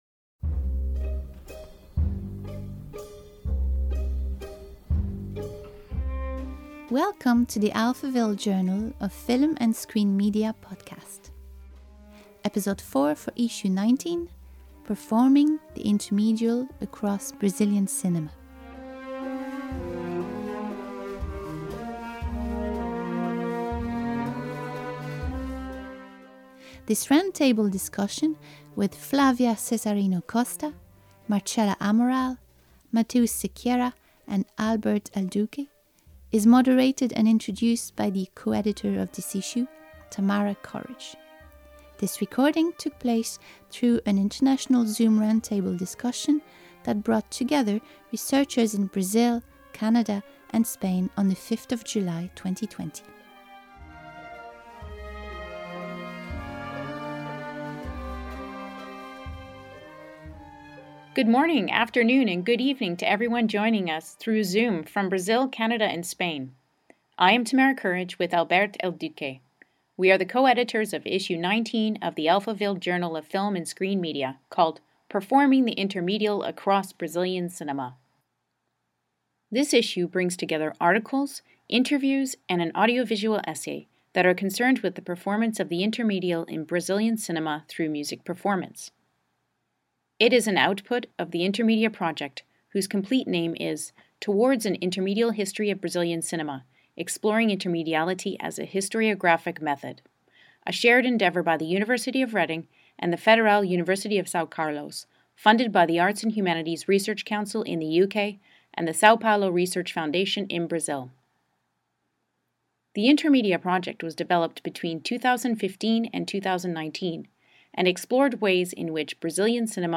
This recording took place through an international Zoom roundtable discussion that brought together researchers in Brazil, Canada and Spain on 5 July 2020.